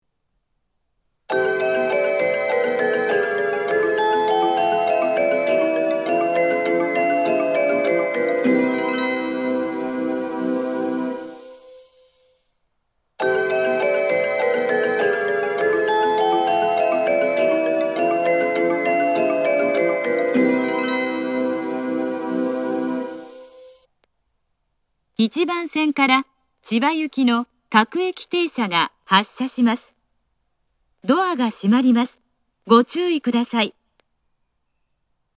音質が大変良いです。
発車メロディー
発車メロディー 1.9コーラスです!ダイヤが厳しめなので、途中切りが多いです。